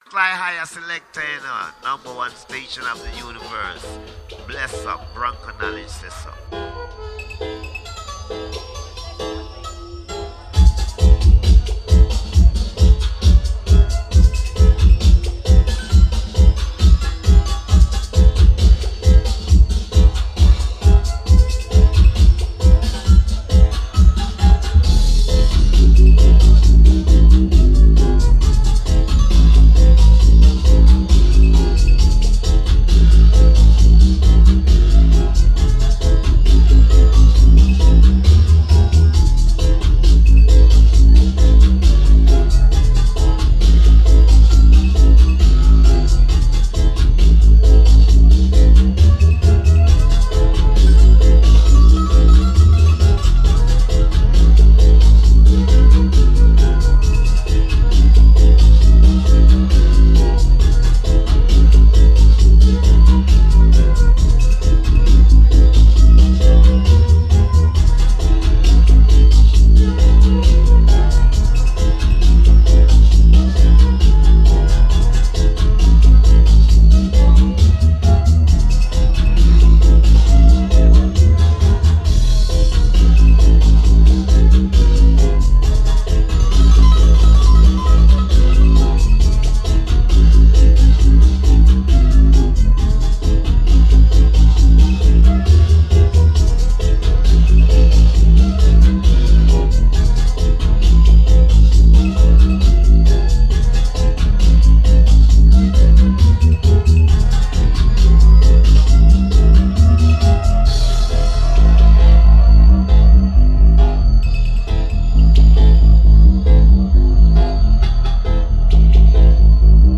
in session
Session full of vibes.